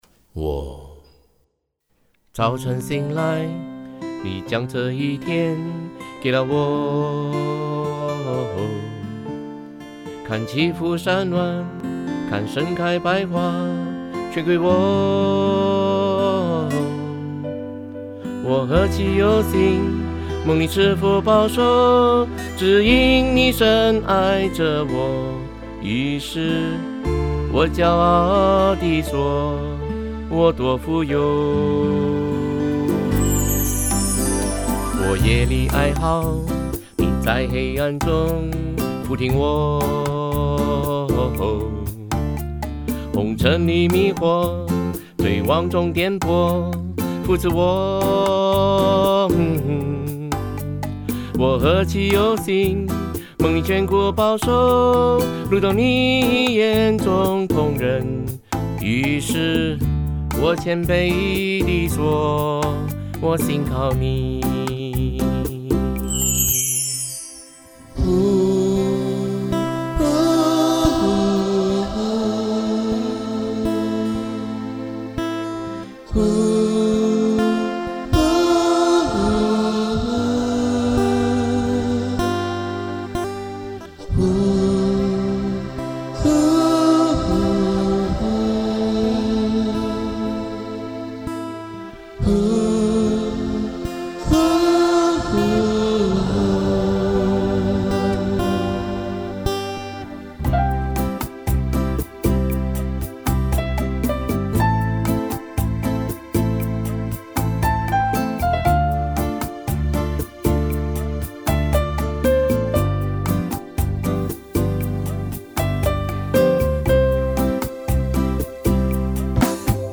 曲风从开始的朴实转为悠长的音乐，借着延绵的“呜”声表示生命中无数次的平安和跌倒犯罪相 叠循环。
第三段开始曲风转入激昂喜乐。